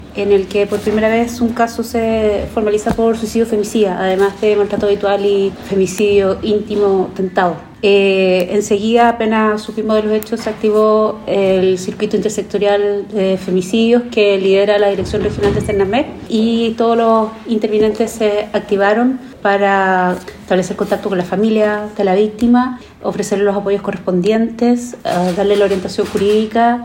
La seremi de la Mujer y Equidad de Género, Francisca Corbalán, dijo que esta formalización es inédita a nivel regional, desde que se aprobó la denominada Ley Antonia.